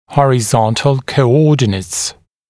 [ˌhɔrɪ’zɔntl kəu’ɔːdɪnəts][ˌхори’зонтл коу’о:динэтс]горизонтальные координаты